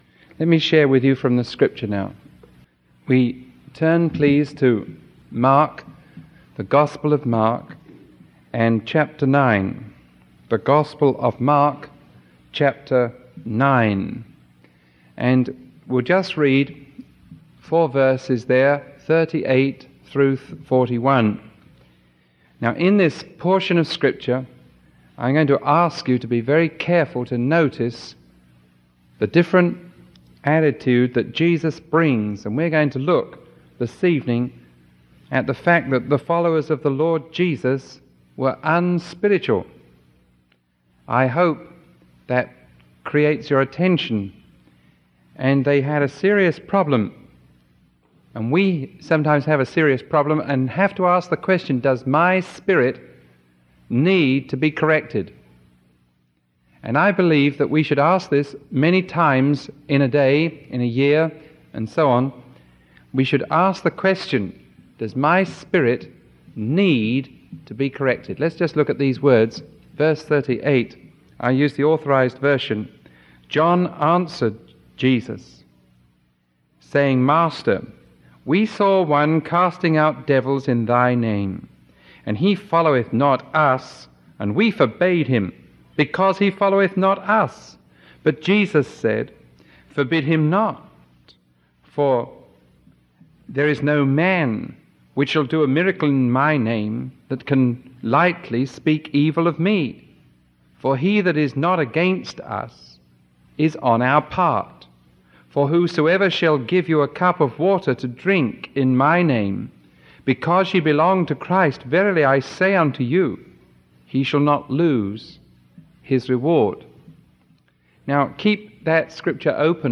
Sermon 0072AB recorded on July 11